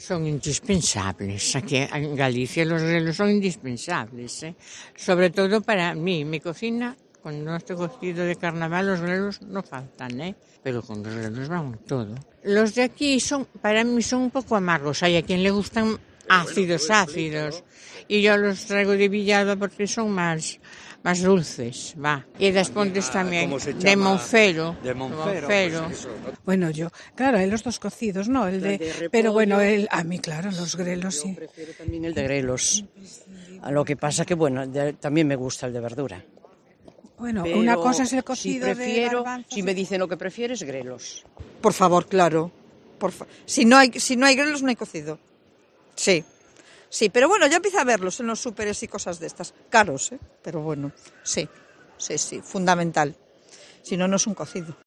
En la calle COPE Ferrol ha comprobado que el grelo gallego es un producto apreciado y que no hay buen cocido sin buenos grelos.
La ciudadanía habla del aprecio a los grelos de la zona